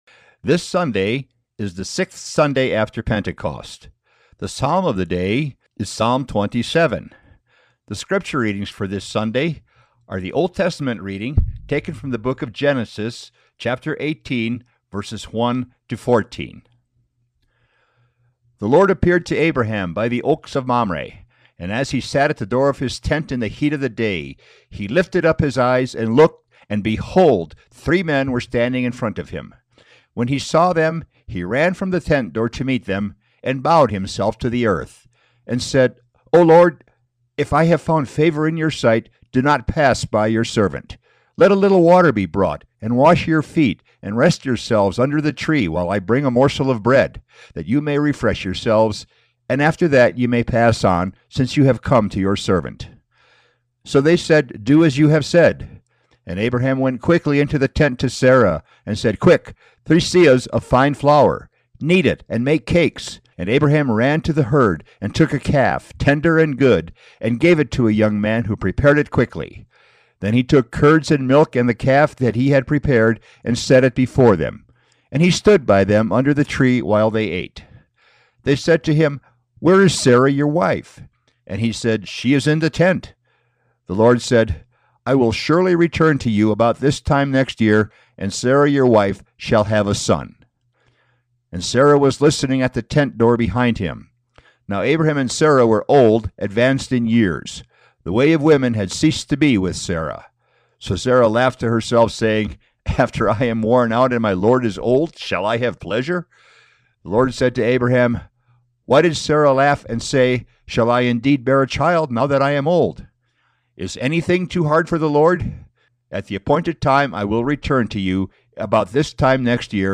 Sermons | Peace With Christ Lutheran Church